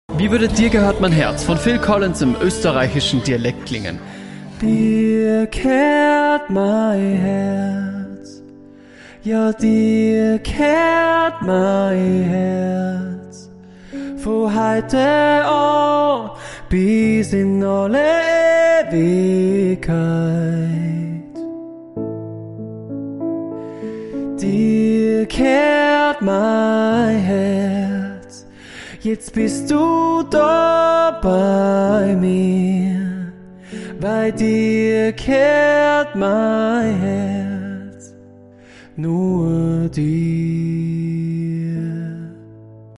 Austro-Pop